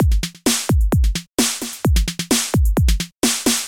标签： 130 bpm Electro Loops Drum Loops 636.23 KB wav Key : Unknown
声道立体声